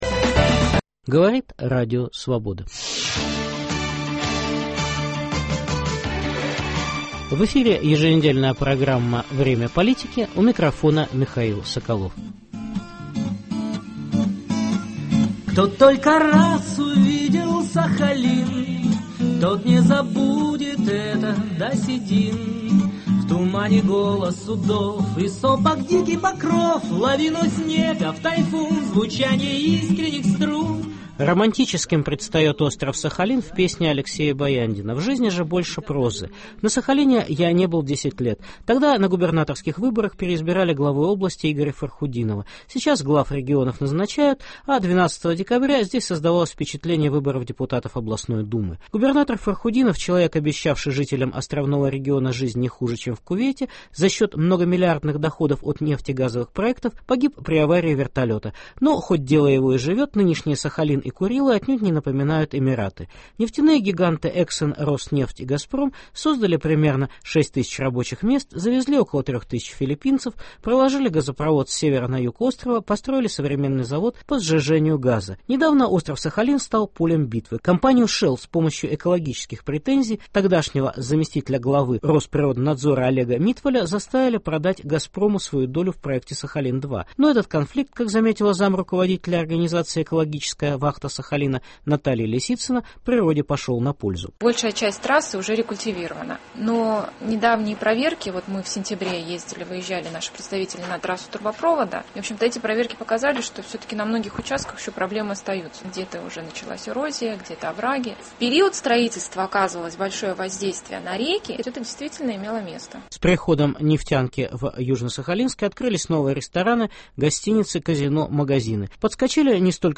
Специальный репортаж